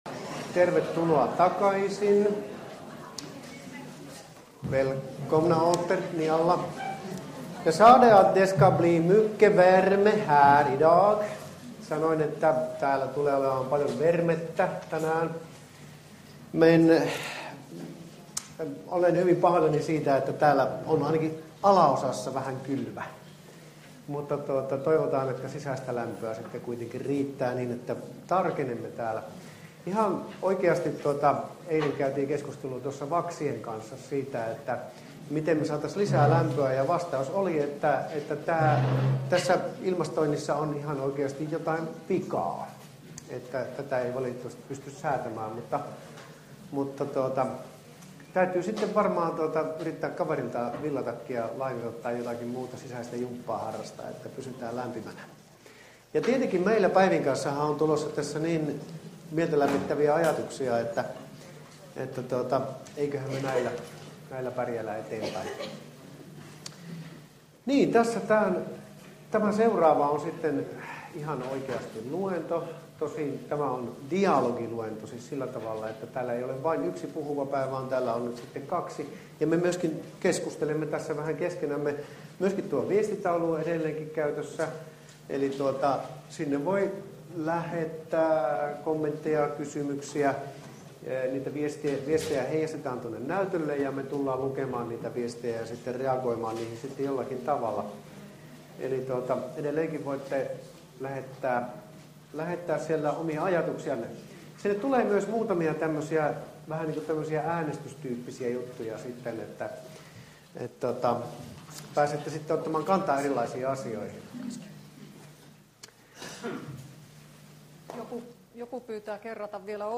Osaava Verme - mentorikoulutuksen valtakunnallinen avausseminaari 2013 - Osa 3 HUOM! Teknisistä ongelmista johtuen vain ääniraita toimii!